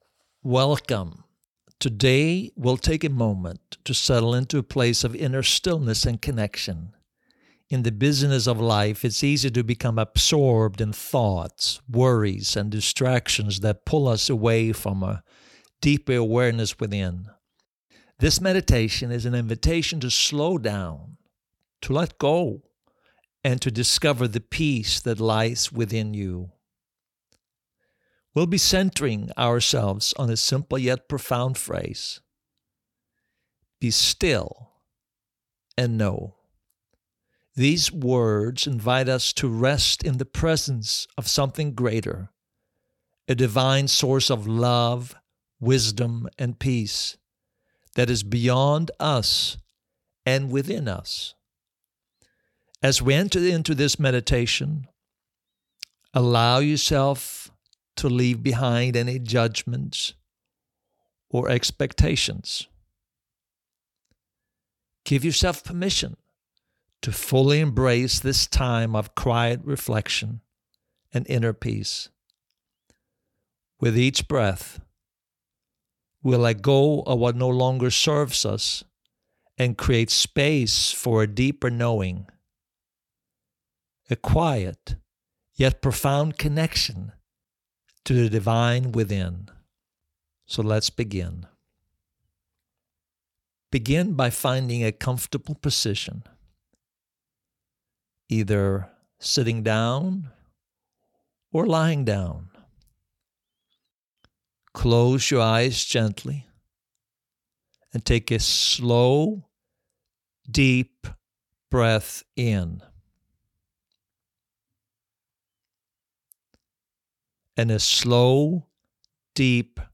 Be-still.-and-know_no-background-music-mixdown.mp3